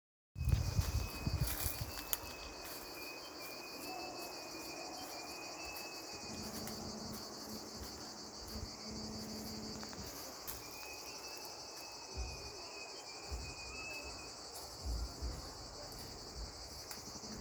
На холме находится сосново-дубовый бор, соответственно, опад состоит по большей части из хвои и дубовых листьев.
Высоко в соснах были слышны протяжные птичьи "трели".